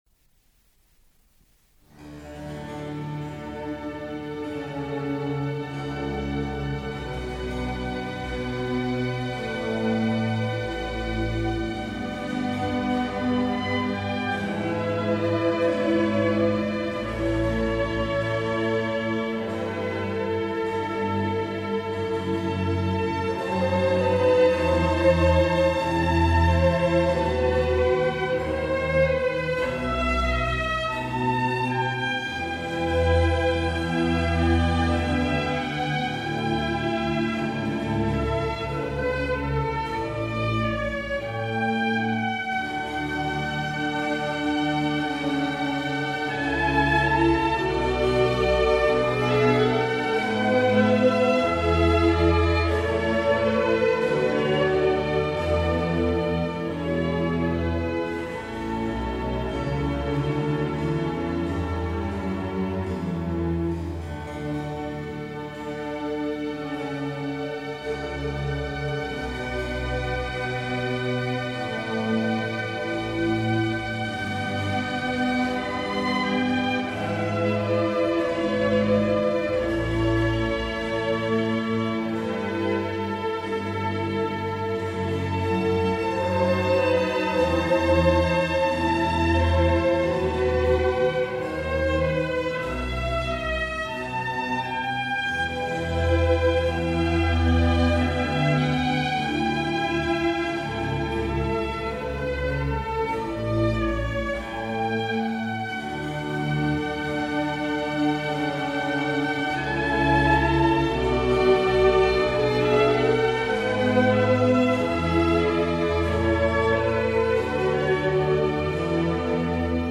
F01-01 Bach Suite 3 Air (famous – largo) | Miles Christi